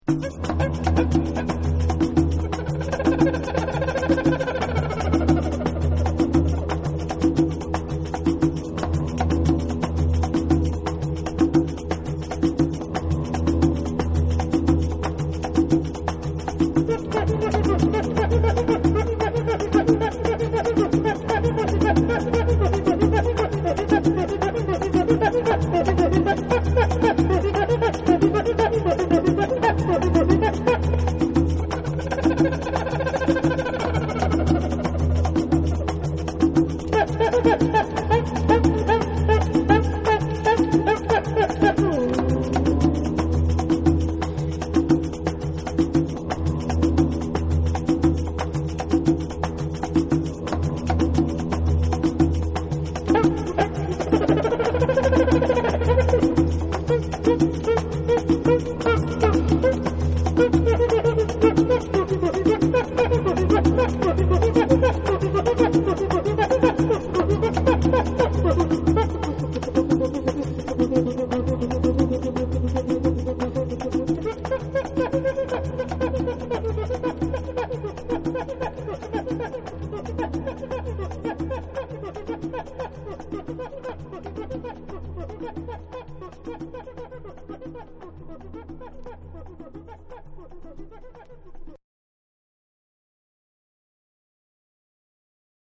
TECHNO & HOUSE / ORGANIC GROOVE